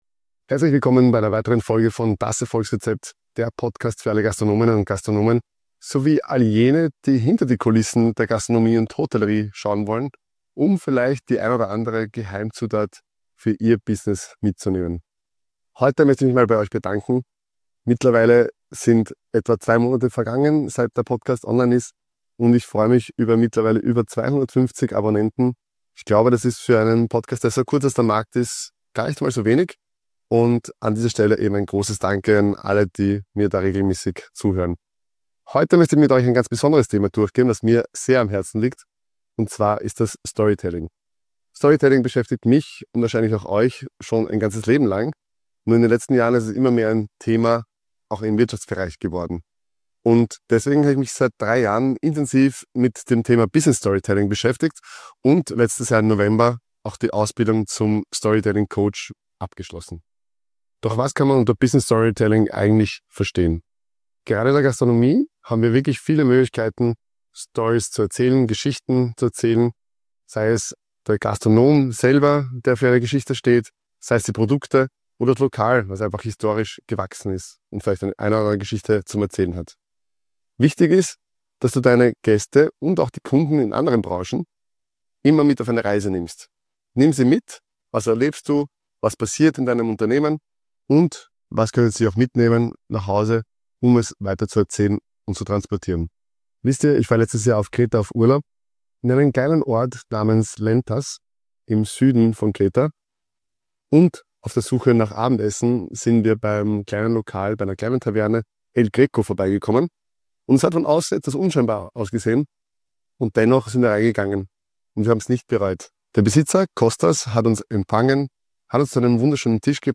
In dieser Solo-Folge von Das Erfolgsrezept teile ich 3 konkrete Tipps, wie du Storytelling gezielt in deinem Unternehmen oder deiner Gastronomie einsetzen kannst, um Gäste, Kunden oder Mitarbeitende zu erreichen – und n...